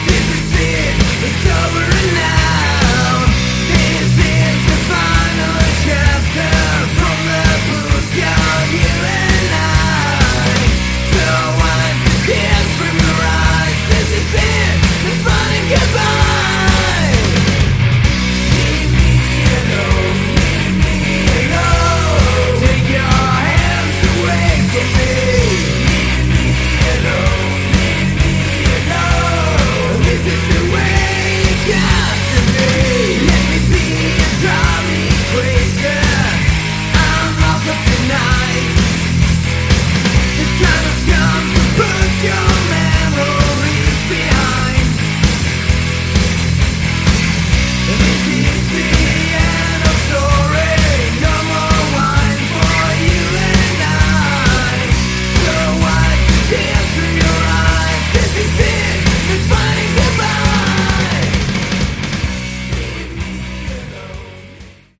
Category: Glam
guitar
vocal, bass
drums
Glam metal.
Cool riffs, good solid sound.